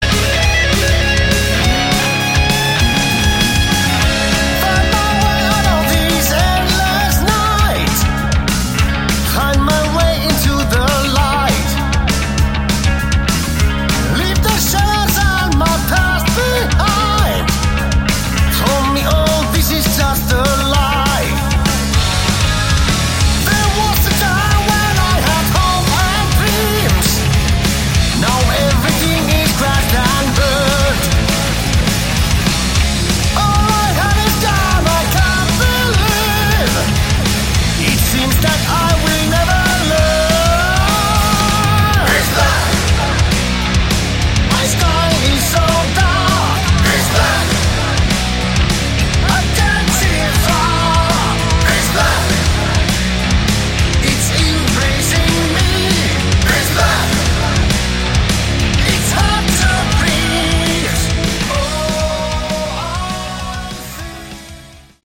Category: Melodic Metal
Vocals
Bass
Drums
Guitar